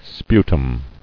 [spu·tum]